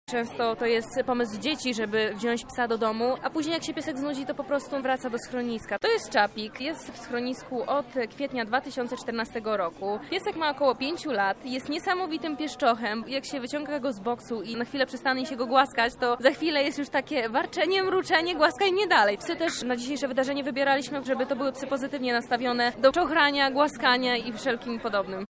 ZooPark w Lublinie